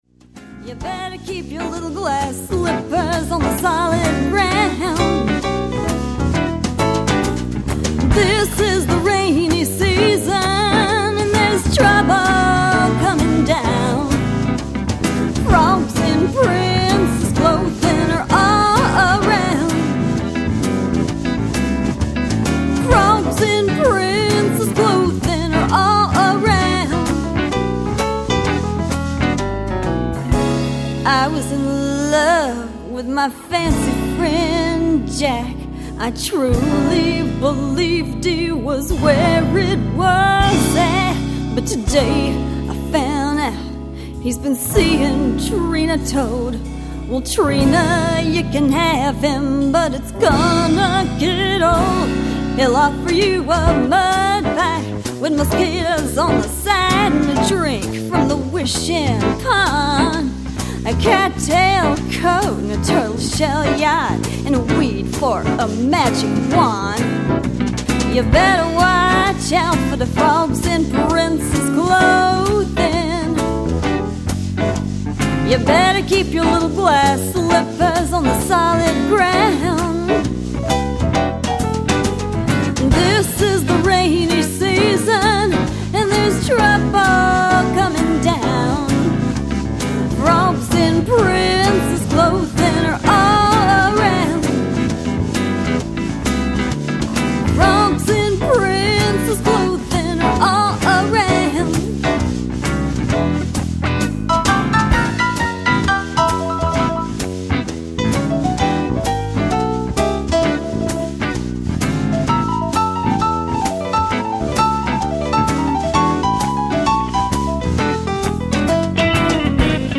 jazzy rock/blues album
this jazzy number is for you!